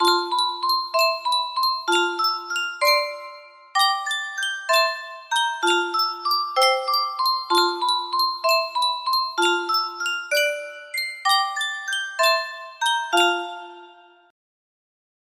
Sankyo Music Box - Melody in F PEQ music box melody
Full range 60